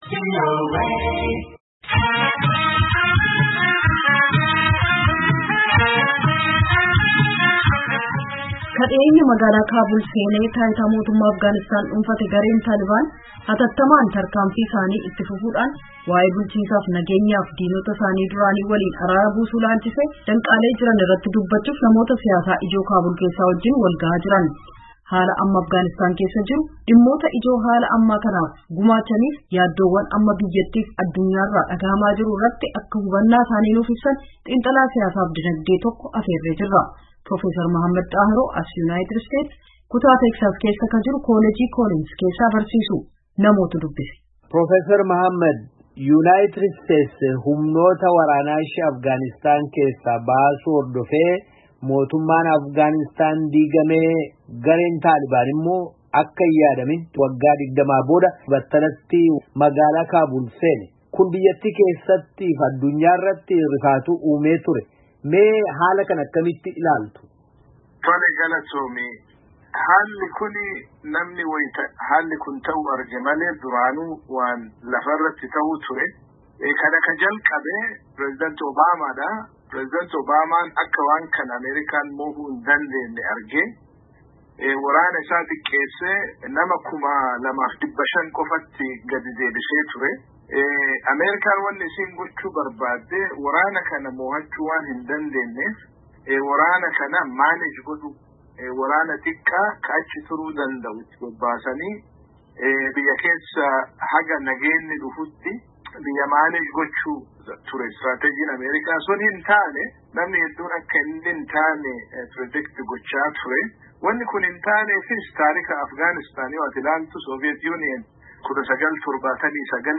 Haala amma Afghaanistaan keessa jirtu, dhimmoota ijoo haala ammaa kanaaf gumaachanii fi yaaddowwan amma biyyattii fi addunyaa irraa dhaga’amaa jiru irratti akka hubannaa isaanii nuuf ibsan, xiinxalaa siyaasaa fi dinagdee tokko afeerree jirra.